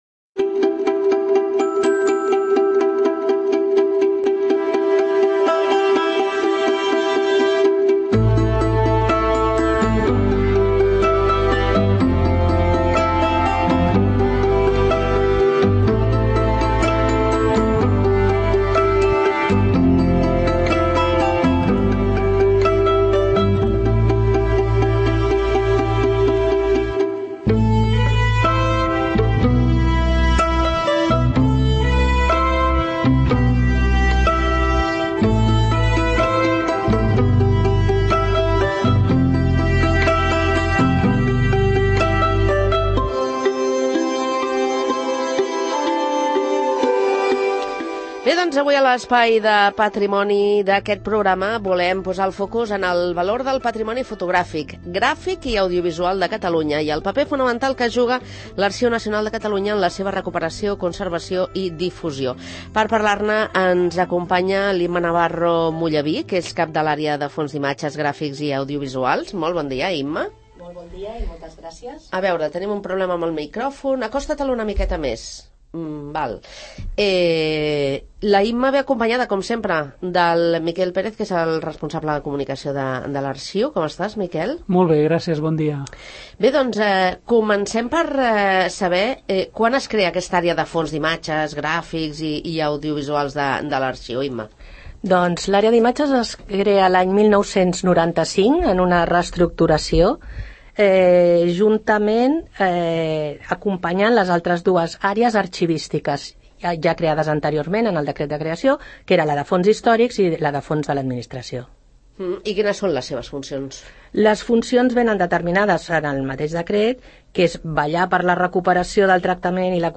a l'estudi Ramon Barnils de R�dio Sant Cugat